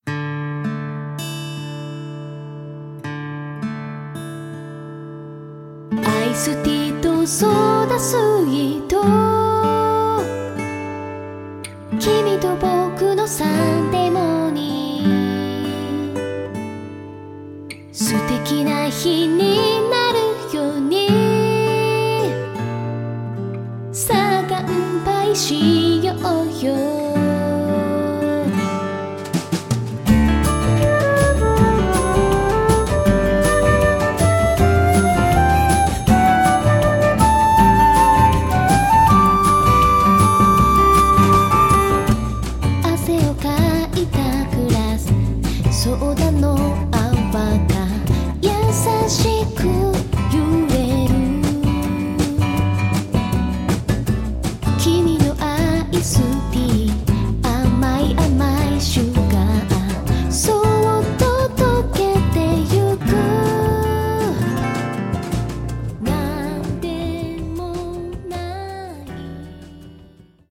歌詞に込められたメッセージと飾らない真っ直ぐで優しくも力強い歌声。
そして、フルートの音色が心地よく心に響き、極彩色の音と言葉が、夢と勇気と希望を与えてくれる一枚！